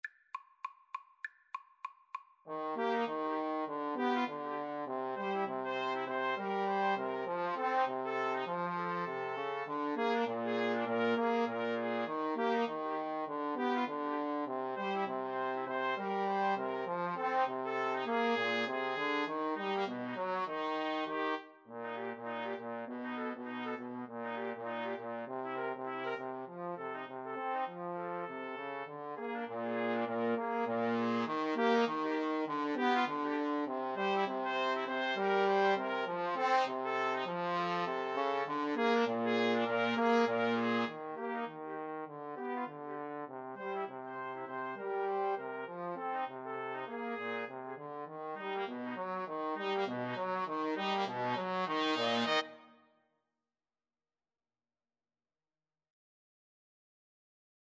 Quick Swing = c. 100
Jazz (View more Jazz 2-Trumpets-Trombone Music)